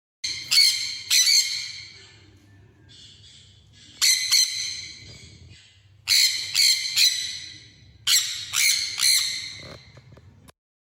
sun-conure-song.mp3